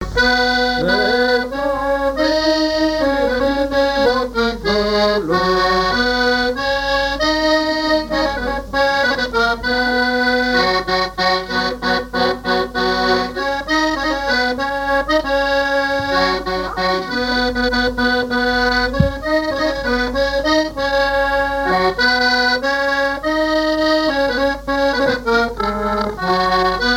gestuel : à marcher
circonstance : fiançaille, noce
Genre laisse
Pièce musicale inédite